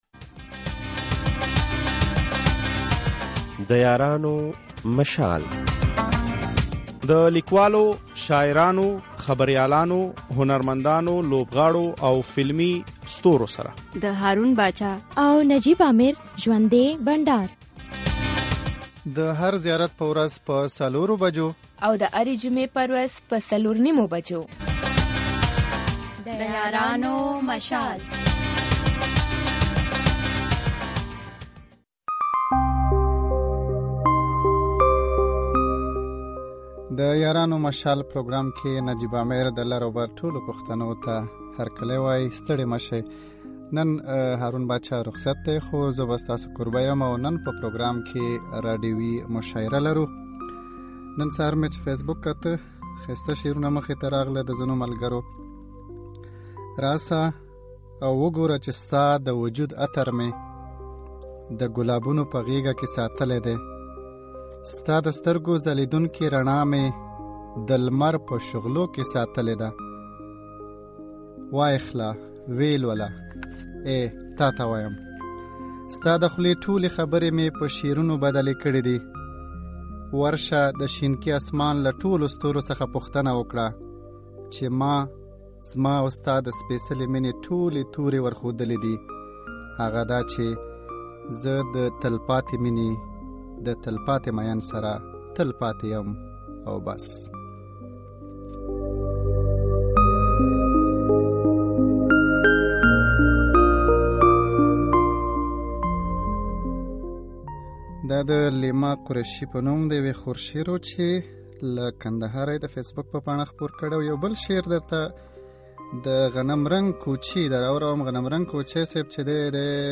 په خپرونه کې د لر اوبر یو شمیر شاعرانو برخه واخیسته او په خپلو غږونو کې یې خپله شاعري تر لوستونکیو ورسوله
پښتو راډیويي مشاعره